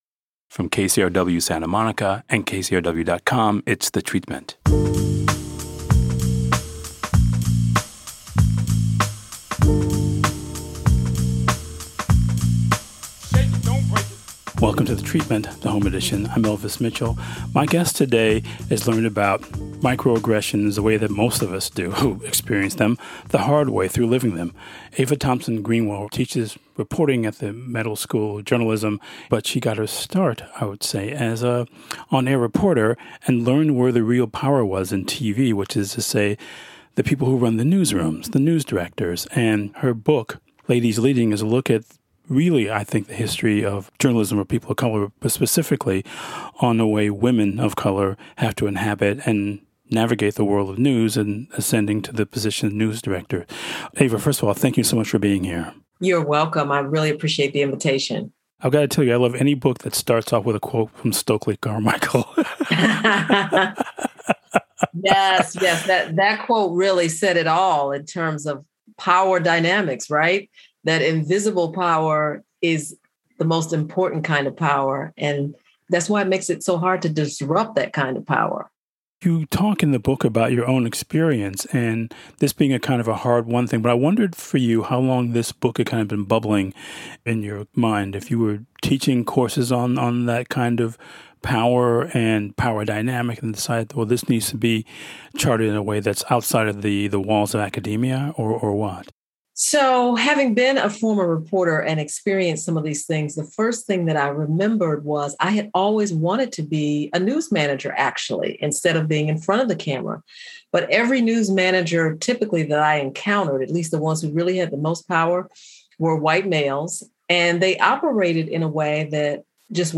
The following interview has been abbreviated and edited for clarity.